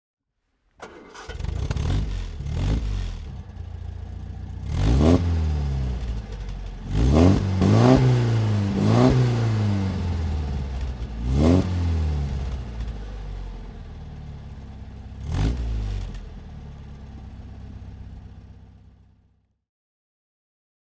Sunbeam Alpine (1960) - Starten und Leerlauf